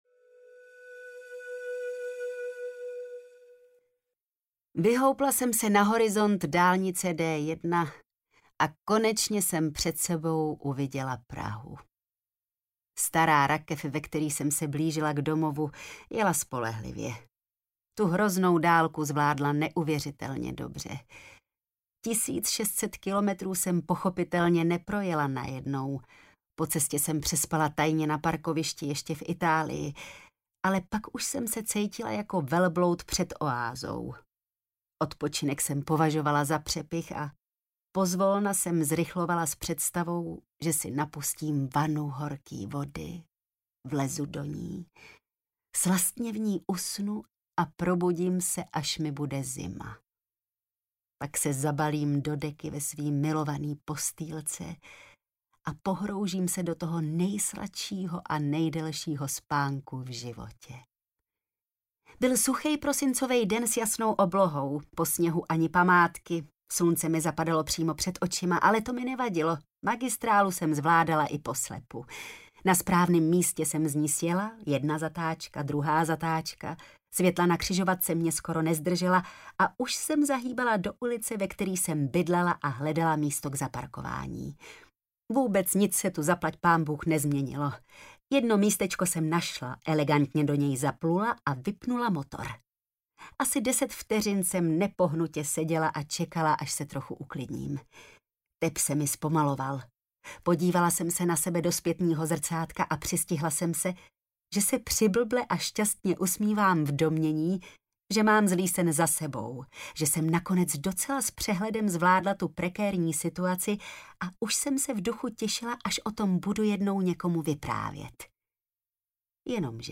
Lesbianka audiokniha
Ukázka z knihy